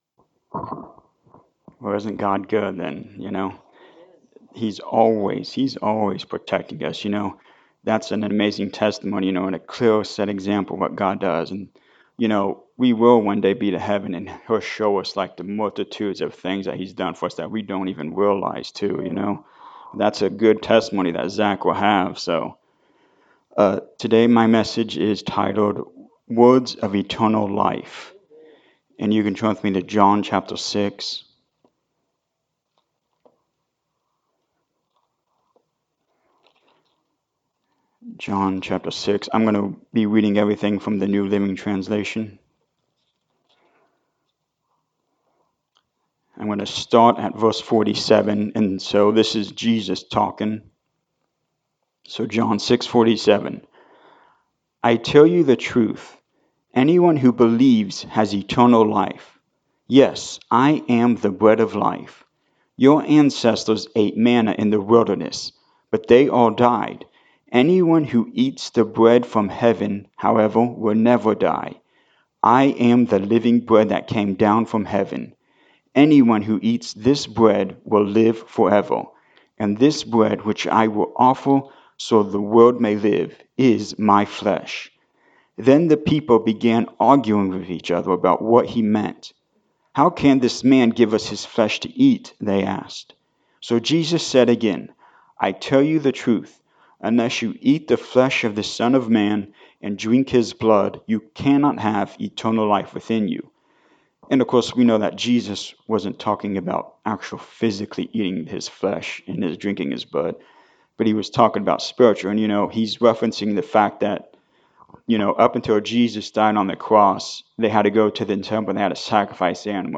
John 6:47-58 Service Type: Sunday Morning Service Jesus was the final one-time sacrifice for all sin.
Sunday-Sermon-for-July-7-2024.mp3